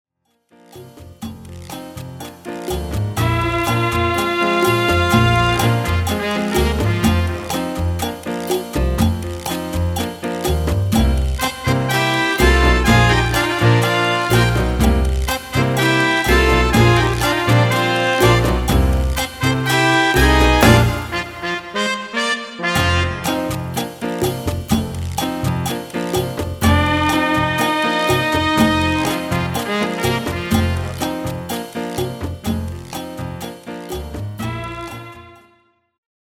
Demo/Koop midifile
Taal uitvoering: Instrumentaal
Genre: Reggae / Latin / Salsa
Originele song is instrumentaal
Demo = Demo midifile
Demo's zijn eigen opnames van onze digitale arrangementen.